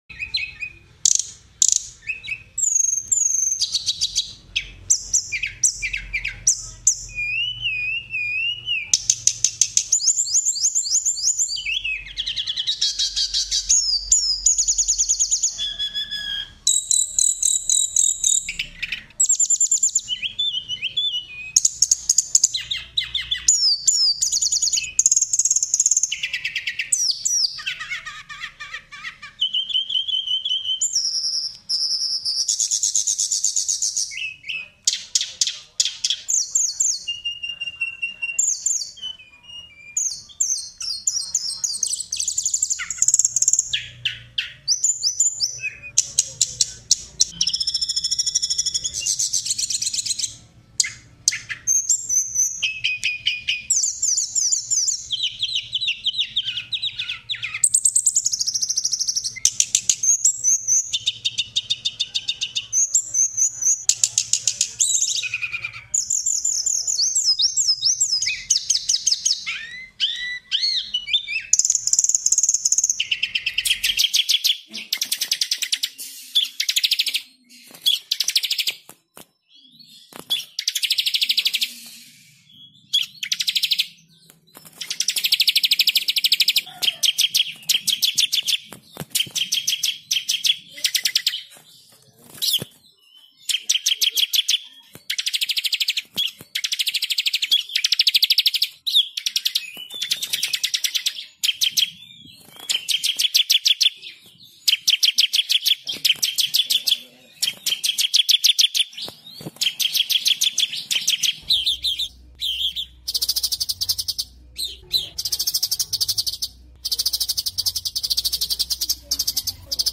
Suara Burung Campuran Masteran MP3 Durasi Panjang
Tag: suara burung campuran suara burung kecil suara masteran burung
🐦✨ Suara burung campuran masteran ini hadir dengan kombinasi kicauan keren yang pas banget untuk melatih burung.
suara-burung-campuran-masteran-durasi-panjang-id-www_tiengdong_com.mp3